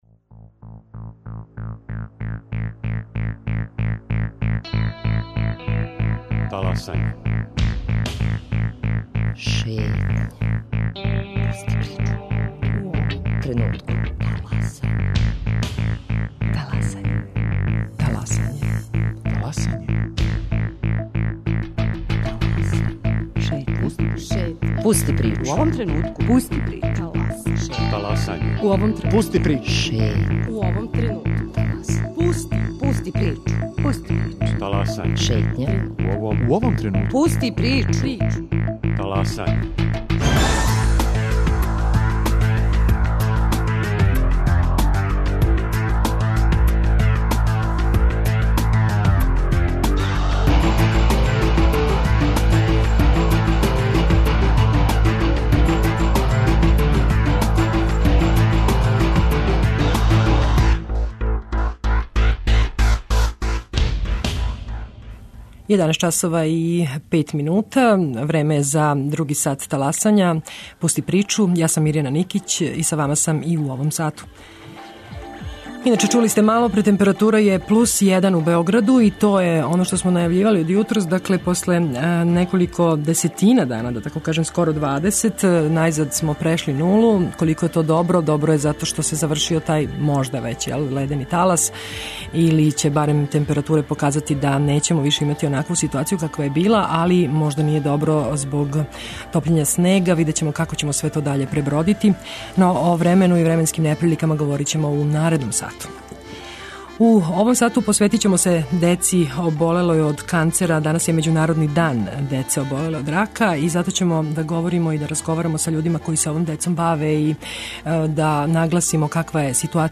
У каквој су ситуацији породице и оболели малишани, како се боре против ове тешке болести, и да ли их друштво заборавља у настрашнијим моментима у животу? Гости емисије су лекар - онколог, психолог и чланови НУРДОР-а.